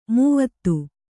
♪ mūvattu